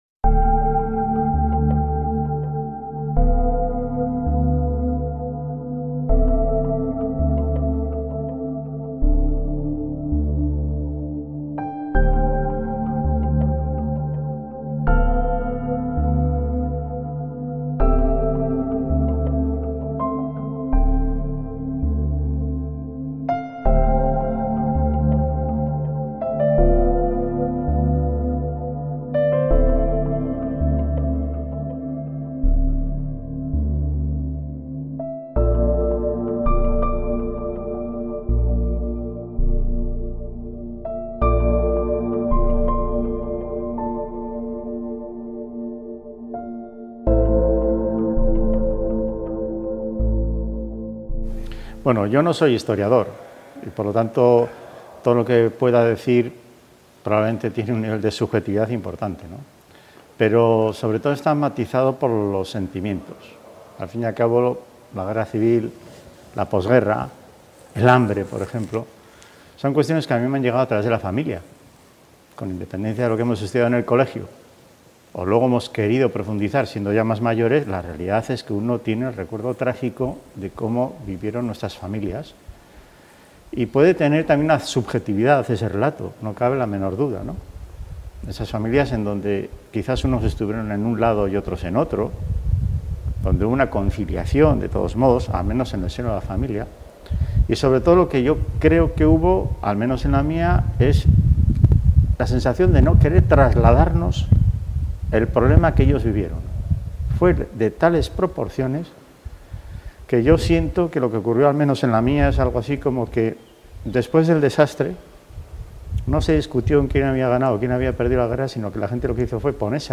Eusko Ikaskuntzako Solasaldiak Dos de los principales especialistas vascos en memoria histórica dialogan sobre la investigación y la transmisión intergeneracional de la Guerra Civil de 1936-1939